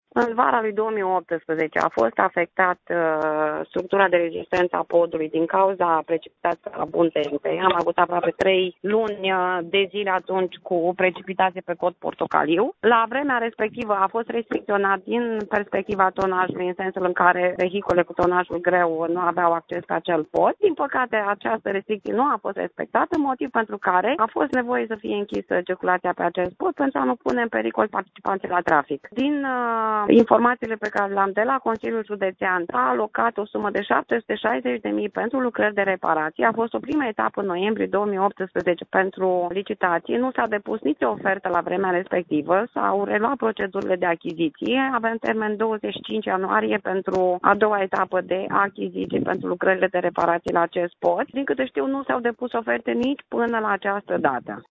Prefectul judeţului Suceava, Mirela Adomnicăi, a explicat că structura de rezistenţă a podului a fost afectată de precipitaţiile de vara trecută, şi nu a fost respectată restricţia de tonaj.